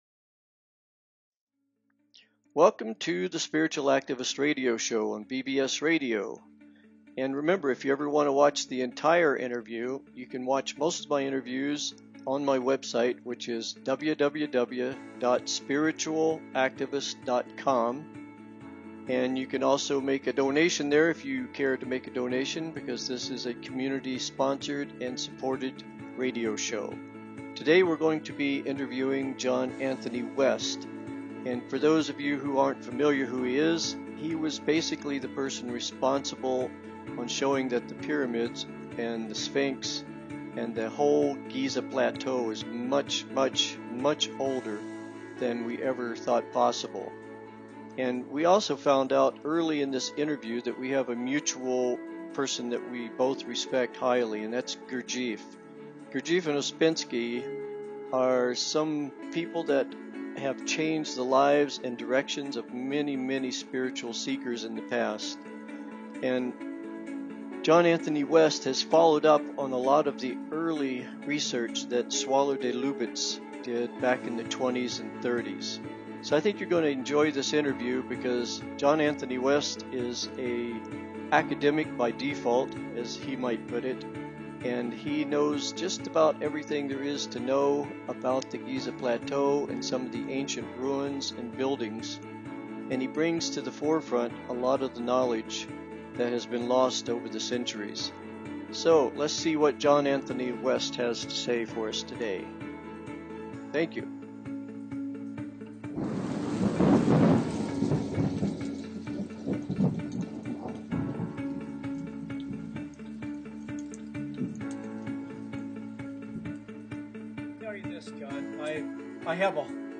Guest, John Anthony West, leading authority and proponent of the 'Symbolist' school of Egyptology